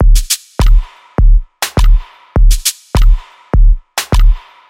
热带屋3鼓声
Tag: 102 bpm Electronic Loops Drum Loops 810.80 KB wav Key : Unknown